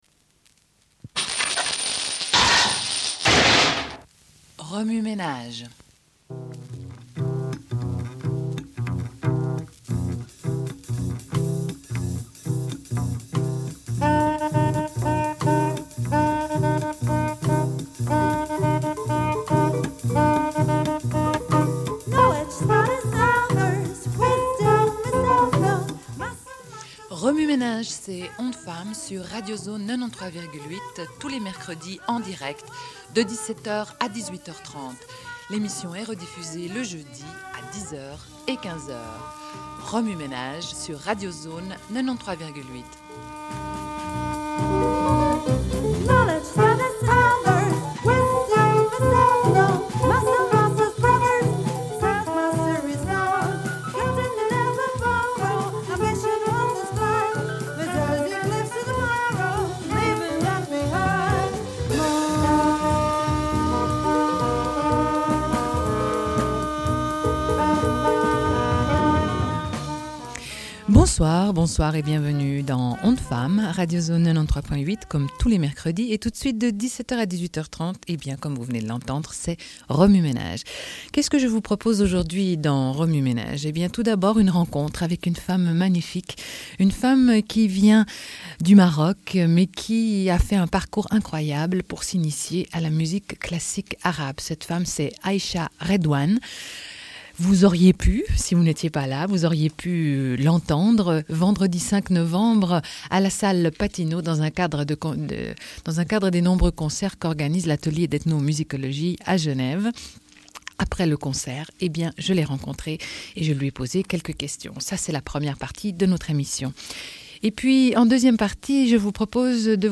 Une cassette audio, face A31:20